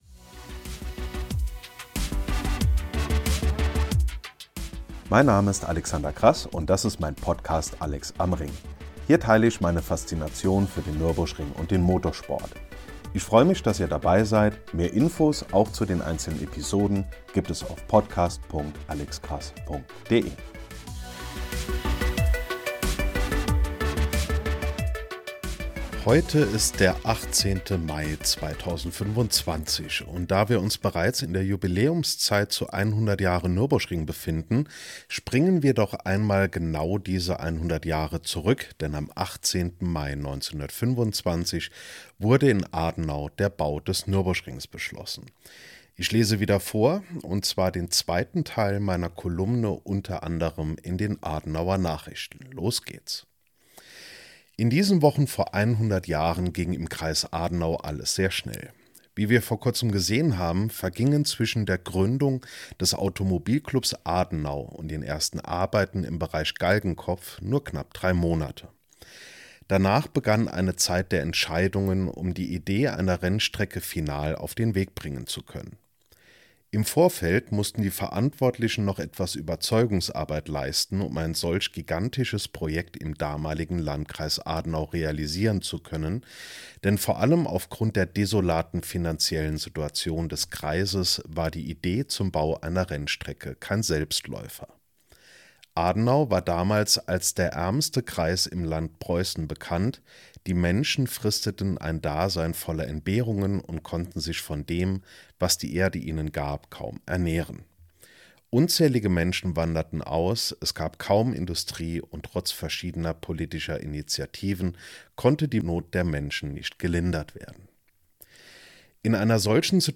Nürburgrings und dazu lese ich den zweiten Teil meiner Kolumne